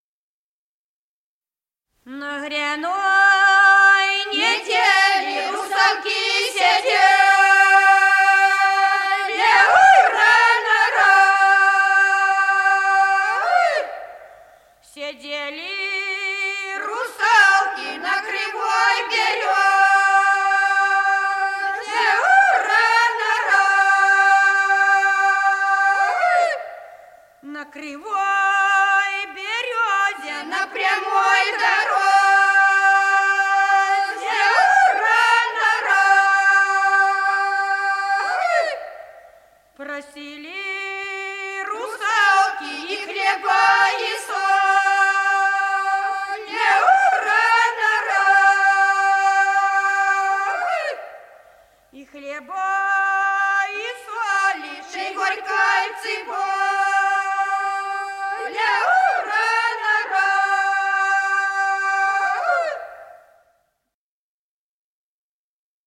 Народные песни Стародубского района «На гряной неделе», гряная.
Записано в Москве, декабрь 1966 г., с. Курковичи.